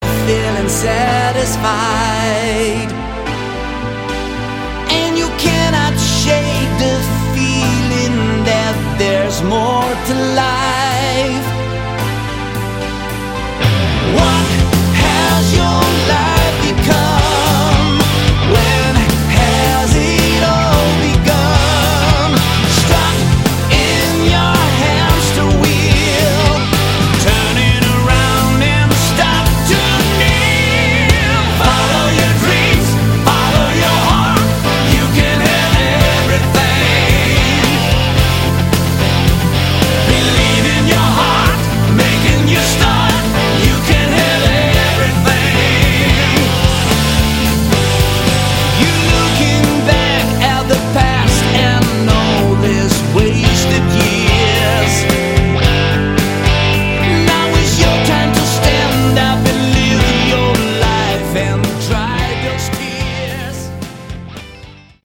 Category: Hard Rock
bass
lead guitar
rhythm guitar
keyboards
drums
lead vocals